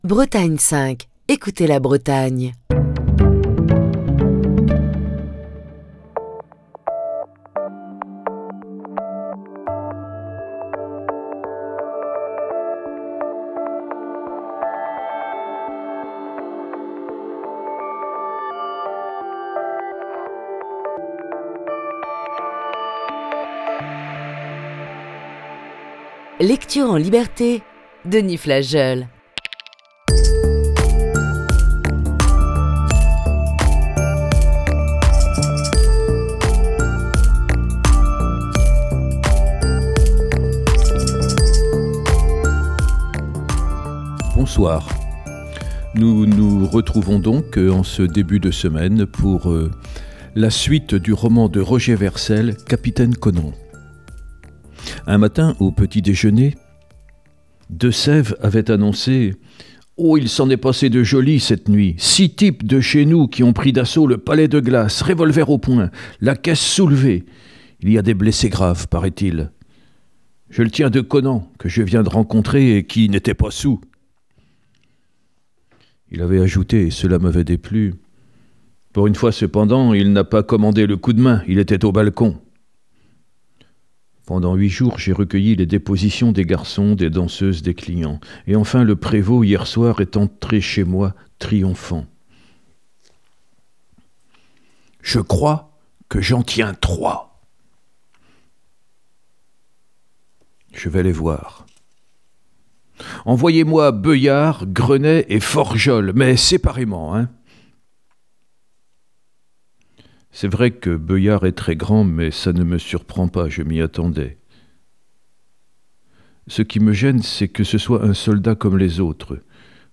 Émission du 16 mai 2022.